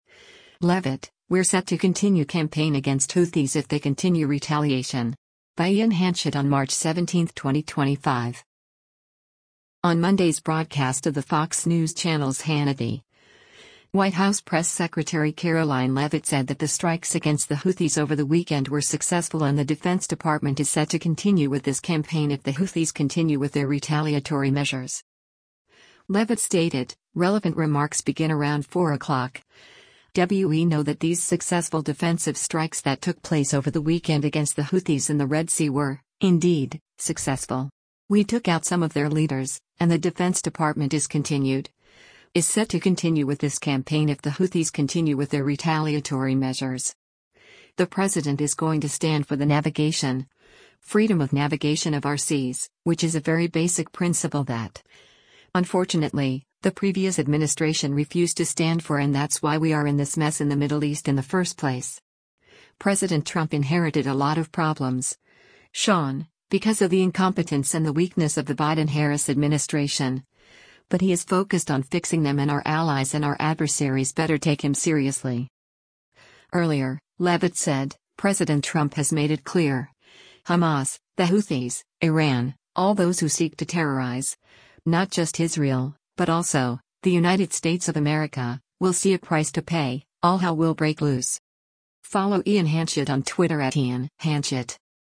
On Monday’s broadcast of the Fox News Channel’s “Hannity,” White House Press Secretary Karoline Leavitt said that the strikes against the Houthis over the weekend were successful and the Defense Department “is set to continue with this campaign if the Houthis continue with their retaliatory measures.”